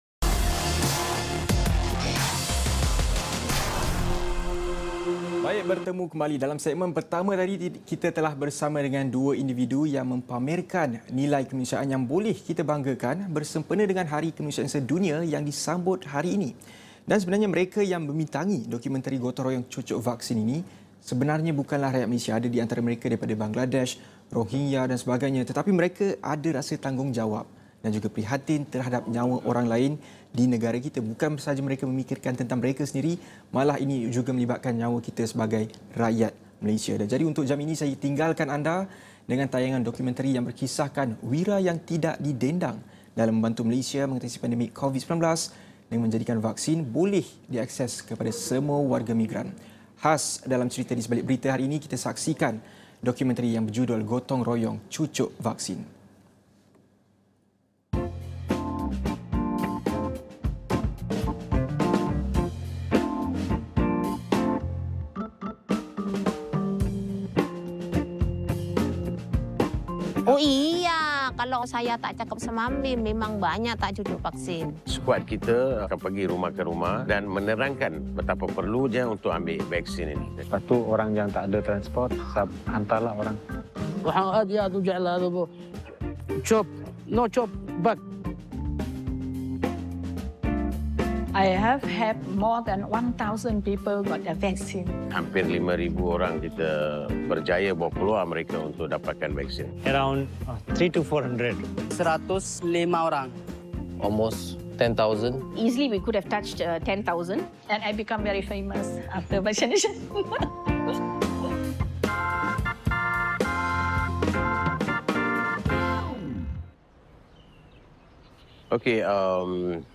Dokumentari 'Gotong Royong Cucuk Vaksin' sempena Hari Kemanusiaan Sedunia.